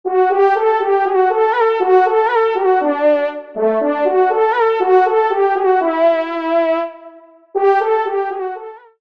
EQUIPAGE RALLYE VAL D’ESSONNE
FANFARE
Extrait de l’audio « Ton de Vènerie »